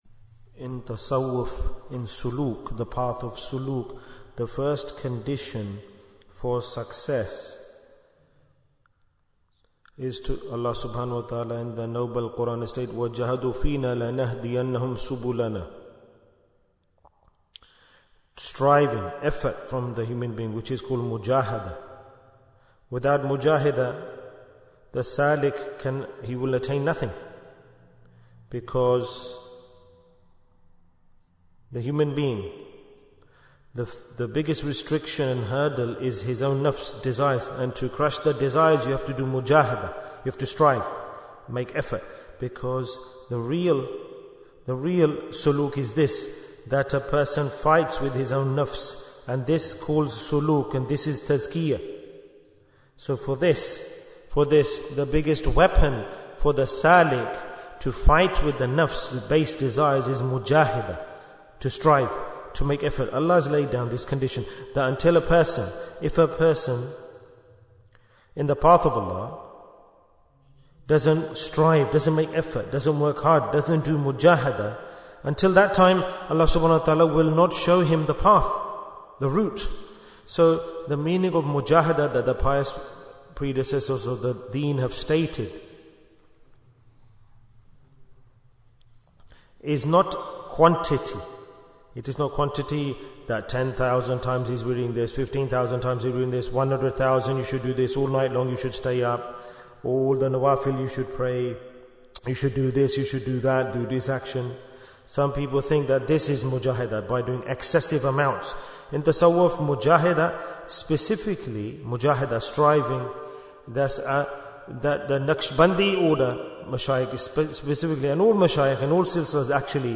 Jewels of Ramadhan 2025 - Episode 17 - Steadfastness Bayan, 21 minutes14th March, 2025